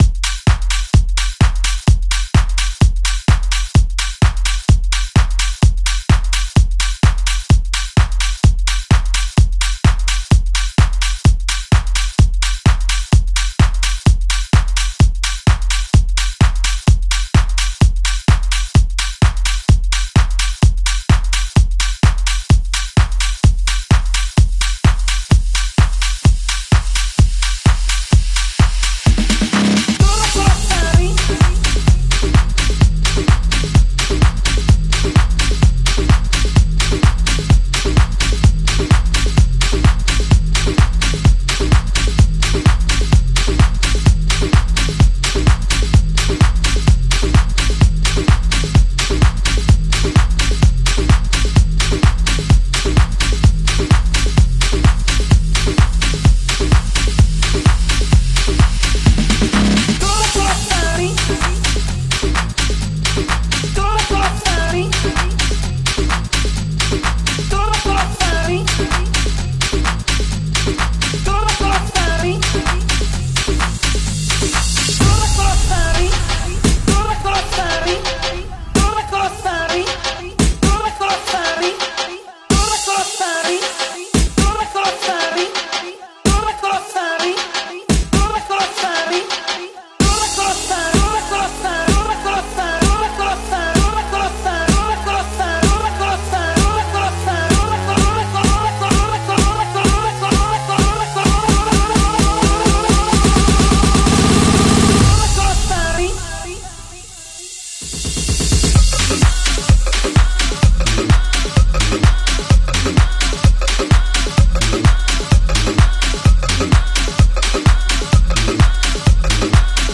Here is a original Latin House track produced by me